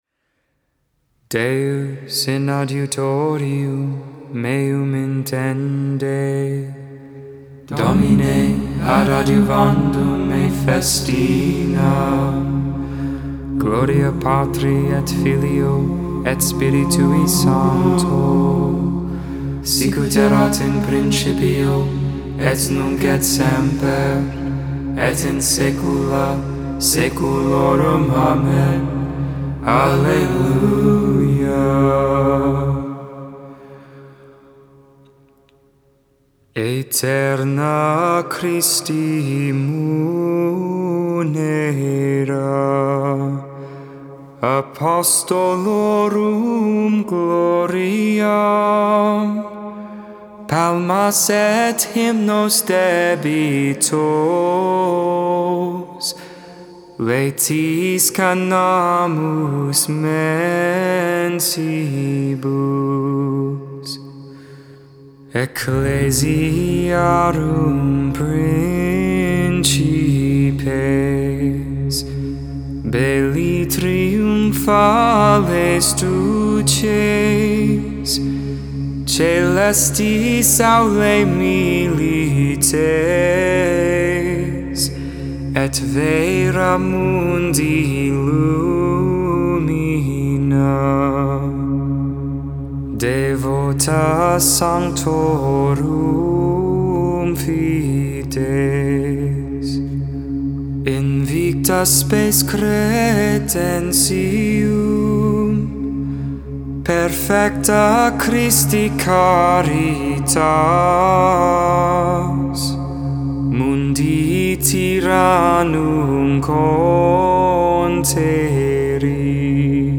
Friday Vespers, Evening Prayer for the 21st Tuesday of Ordinary Time, on the Feast St. Bartholomew, Apostle, August 24th, 2021.
Magnificat: Luke 1v46-55 (English, tone 8) Intercessions: Be mindful of your Church, O Lord.